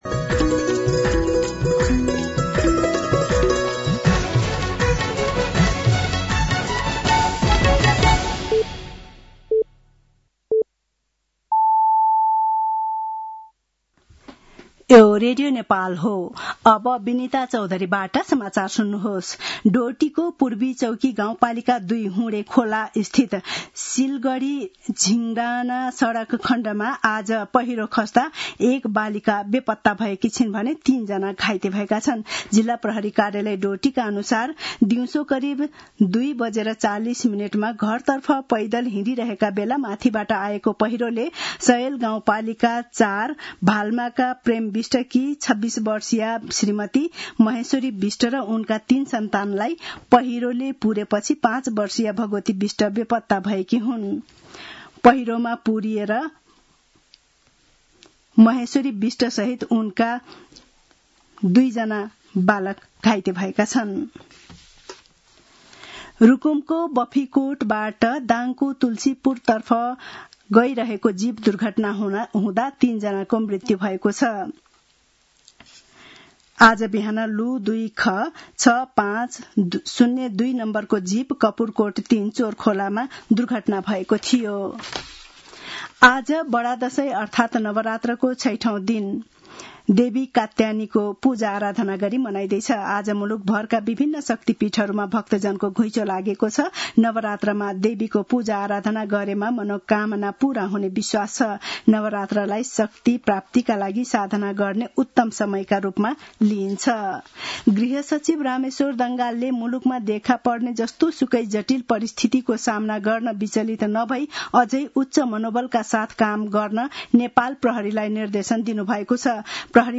साँझ ५ बजेको नेपाली समाचार : १२ असोज , २०८२
5-pm-news-6-12.mp3